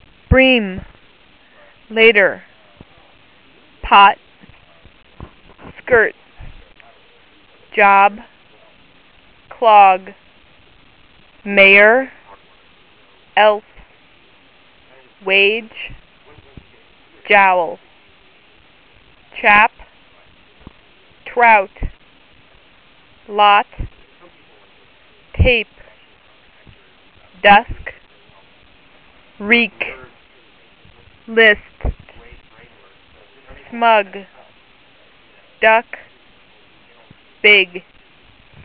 Again, 20 words will be read with an interval of about one second between each word.